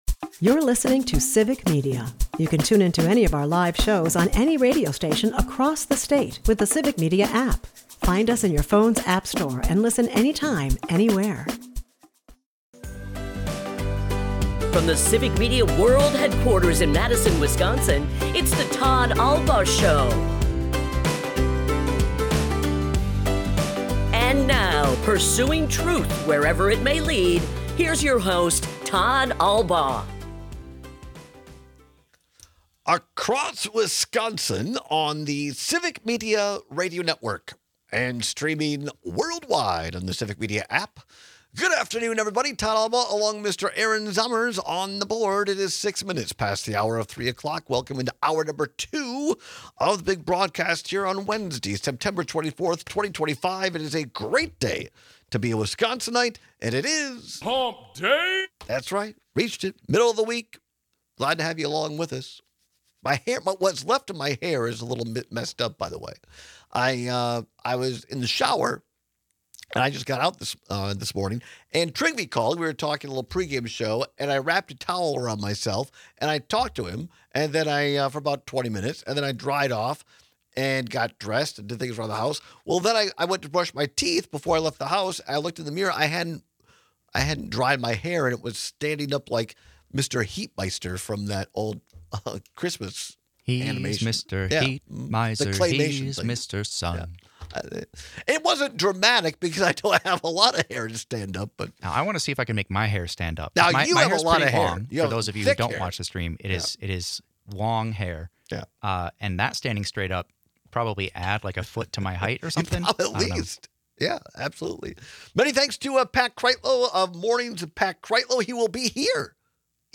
We take your calls and texts on how you prefer to travel. We wrap up today’s show by sharing part of a discussion between legendary broadcaster Bob Costas and Brewers Manager Pat Murphy remembering Bob Uecker.